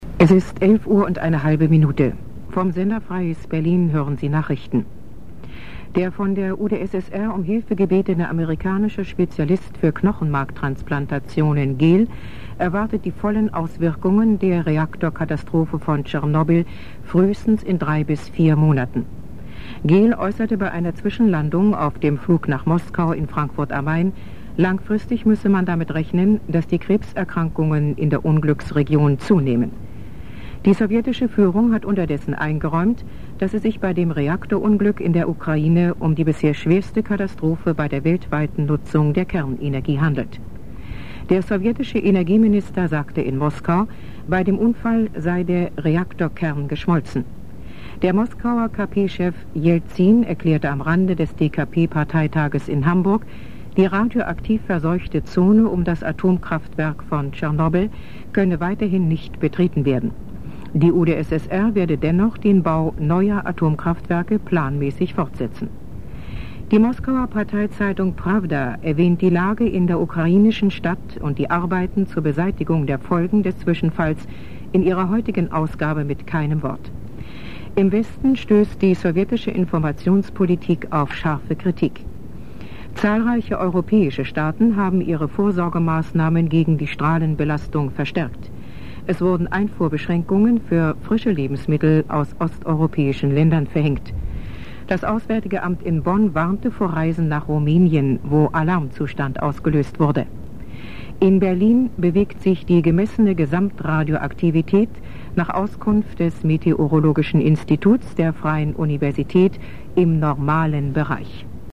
SFB - Nachrichten (1:52)
Die obenstehenden Audiodateien sind Aufnahmen auf Kassettenrekorder direkt (Radio) oder per Mikro (TV) und generell sehr dumpf und (besonders bei den Mikro-Aufnahmen) mit Rauschen unterlegt.